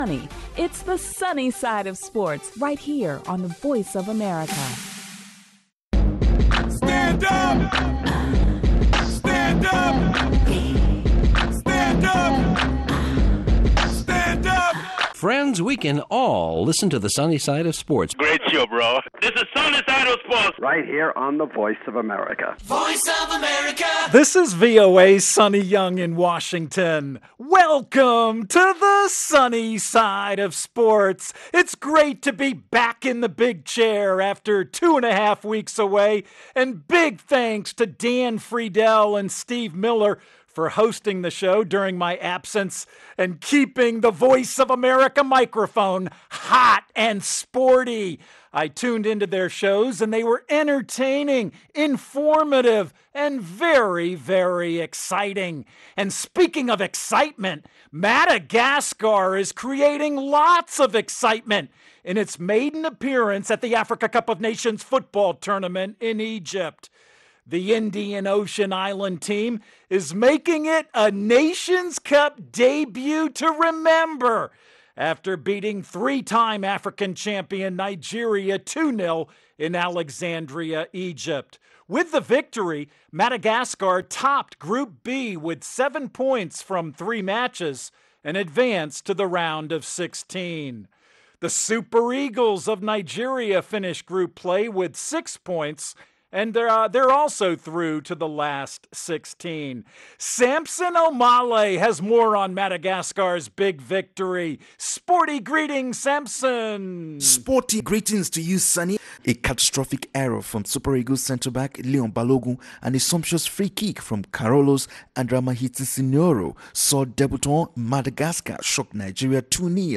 Airing Monday through Friday, this 30-minute program takes a closer look at the stories Africans are talking about, with reports from VOA correspondents, and interviews with top experts and...